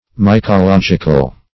Search Result for " mycological" : The Collaborative International Dictionary of English v.0.48: Mycologic \My`co*log"ic\, Mycological \My`co*log"ic*al\, a. Of or relating to mycology, or the fungi.